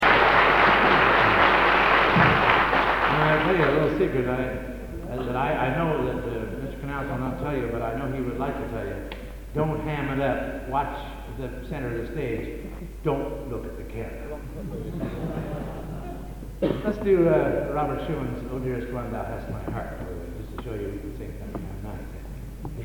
Location: Plymouth, England
Genre: | Type: Director intros, emceeing